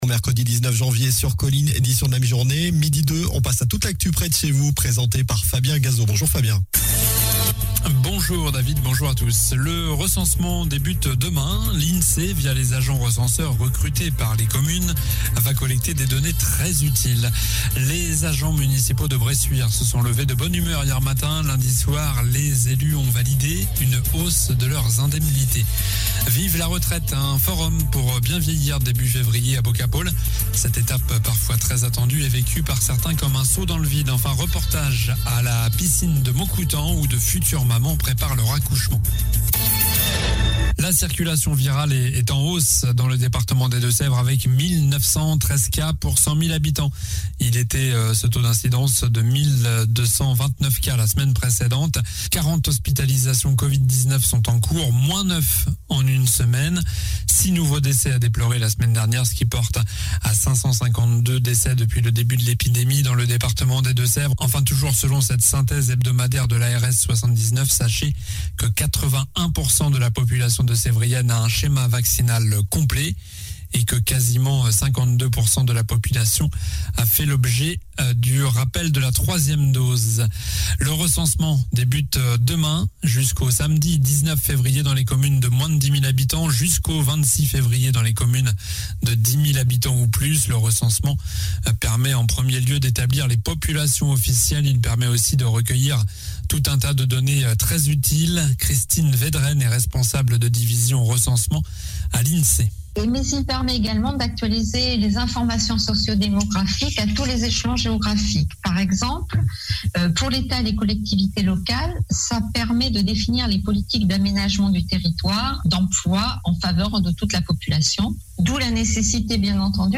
Journal du mercredi 19 janvier (midi)